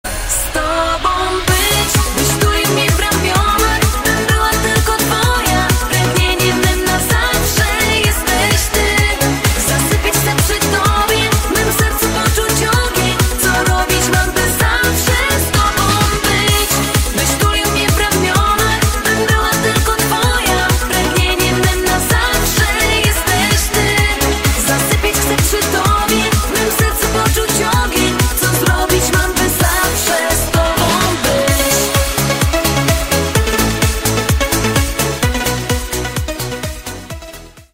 Kategorie Disco Polo